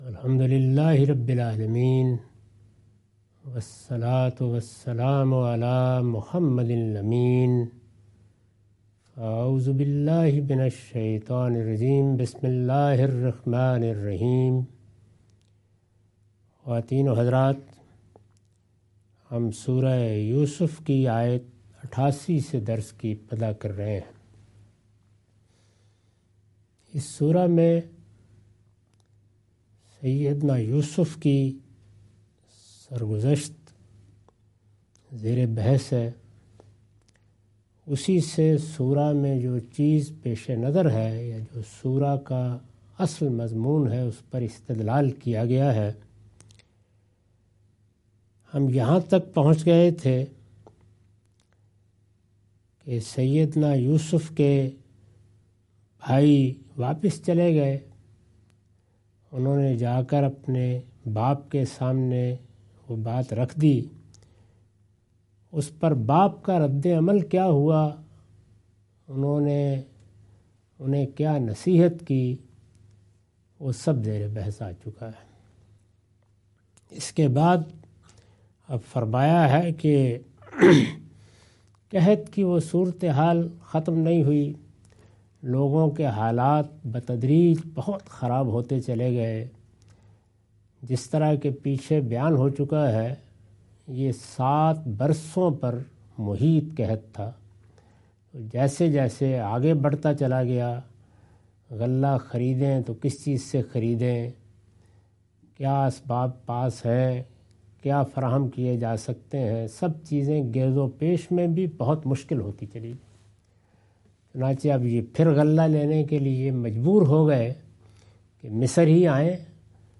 Surah Yousuf - A lecture of Tafseer-ul-Quran – Al-Bayan by Javed Ahmad Ghamidi. Commentary and explanation of verses 88-92.